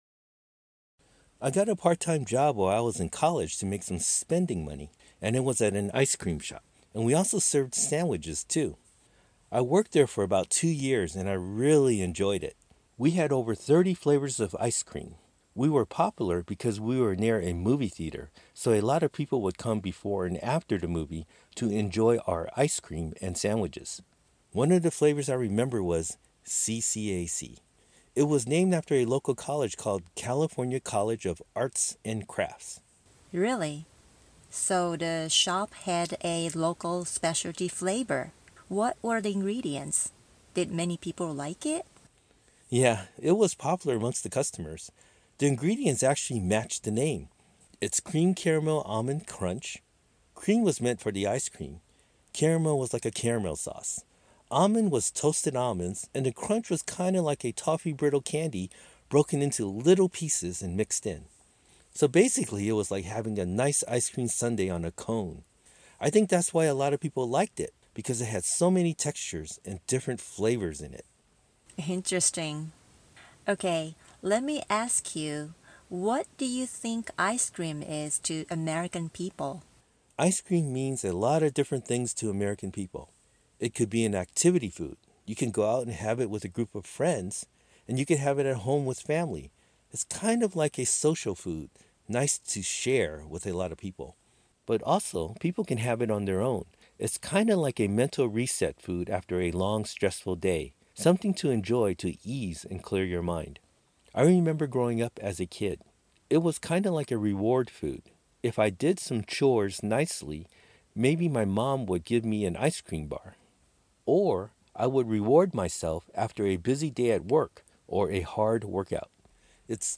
「文法を学ぶための例文」ばかり勉強していませんか？　ここではアメリカ人が、友人や同僚と普通に話している時の「自然な日常会話の表現」を聴くことができます
アメリカ人の夫にインタビュー